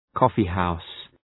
Προφορά
{‘kɔ:fı,haʋs}